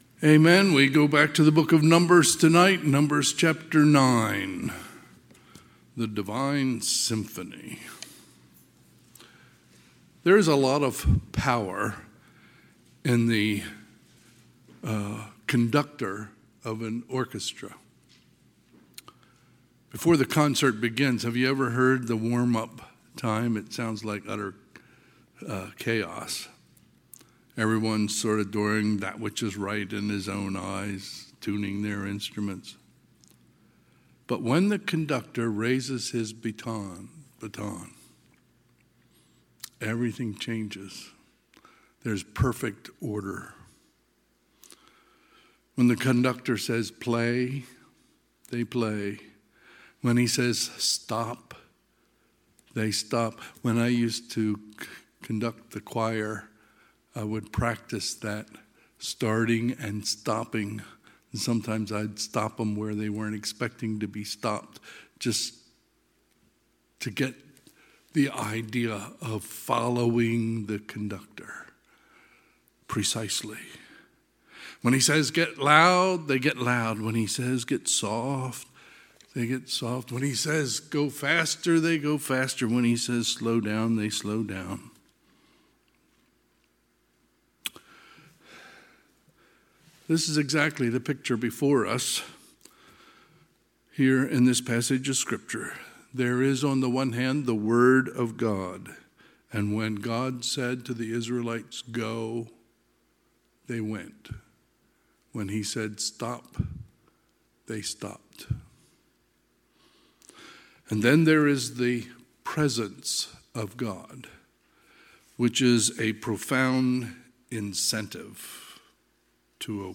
Sunday, December 29, 2024 – Sunday PM
Sermons